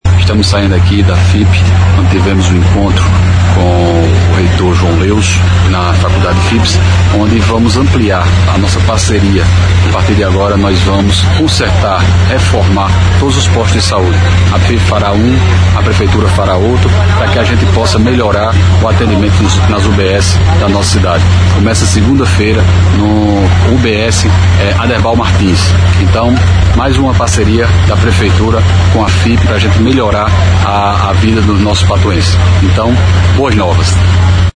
Fala do prefeito Dinaldinho Wanderley –